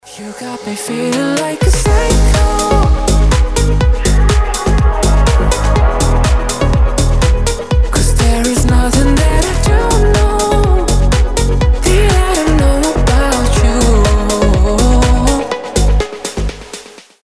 • Качество: 320, Stereo
deep house
женский голос
Vocal House